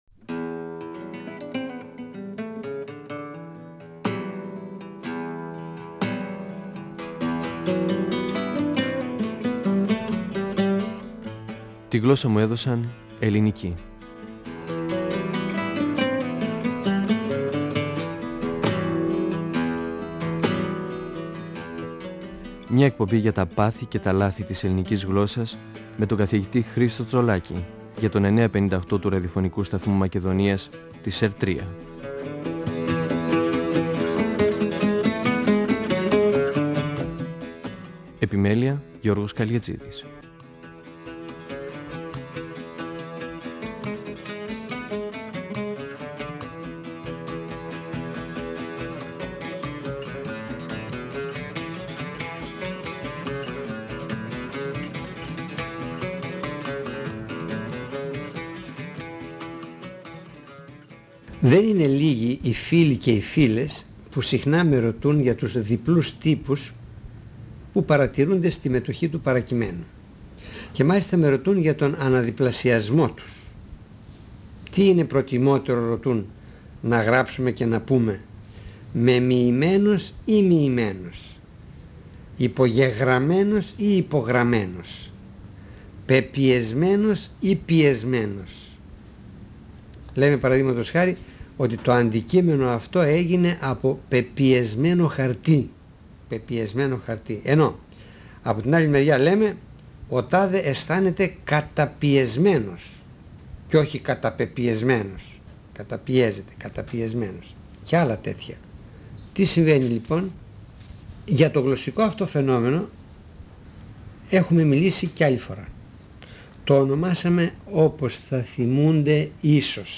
Ο γλωσσολόγος Χρίστος Τσολάκης (1935-2012) μιλά για το φαινόμενο του αναδιπλασιασμού, της διπλομορφίας, τις μετοχές παρακειμένου και τις μετοχές που γίνανε επίθετα.
Νησίδες & 9.58fm, 1999 (πρώτος, δεύτερος, τρίτος τόμος), 2006 (τέταρτος τόμος, πέμπτος τόμος). 958FM Αρχειο Φωνες Τη γλωσσα μου εδωσαν ελληνικη "Φωνές" από το Ραδιοφωνικό Αρχείο Εκπομπές ΕΡΤ3